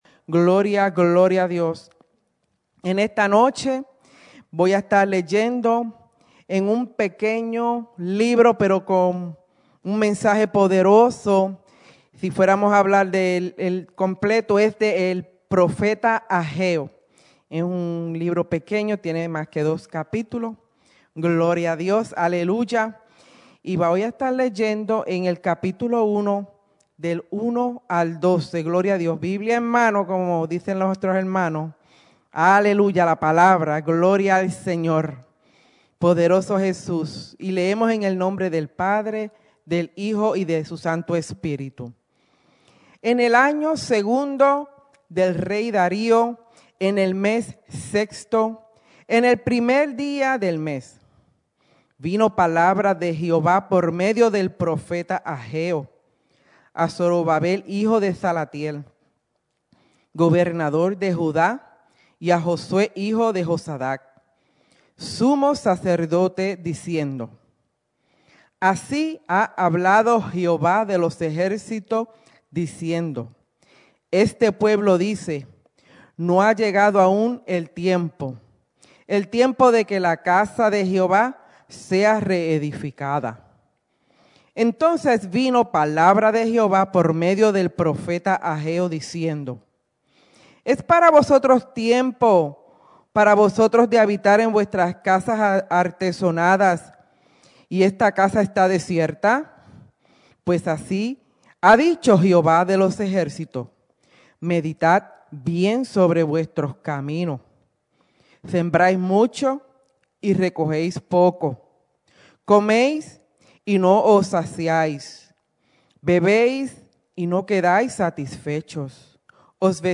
en la Iglesia Misión Evangélica en Souderton, PA